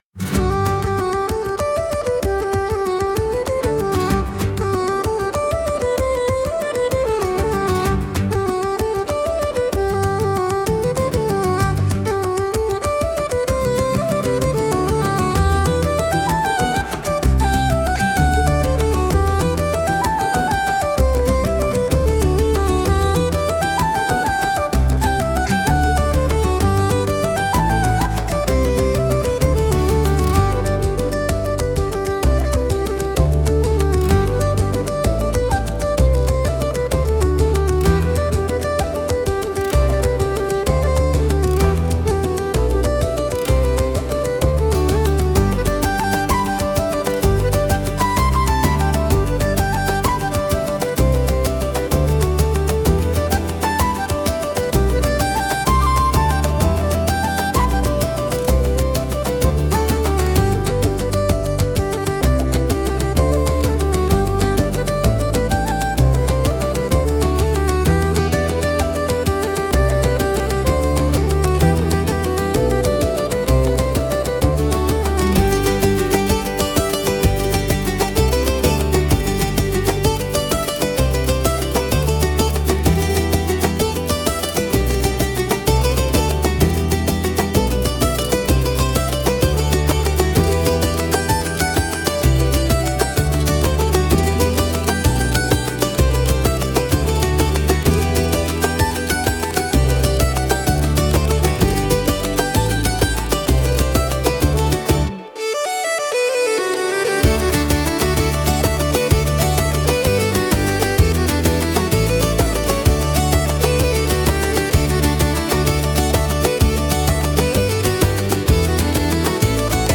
calm instrumental playlist